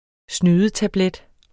Udtale [ ˈsnyːðəˌ- ]